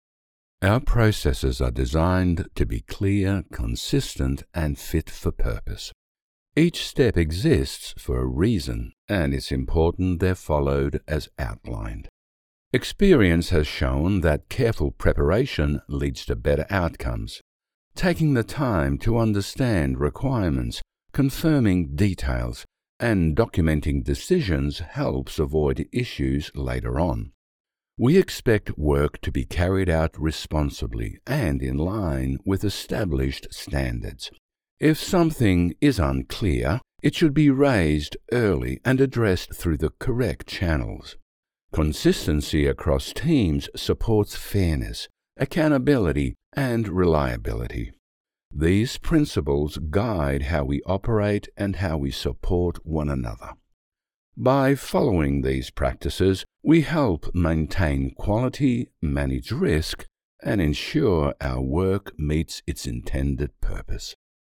Explainer & Whiteboard Video Voice Overs
Older Sound (50+)
0225Corporate_Explainer.mp3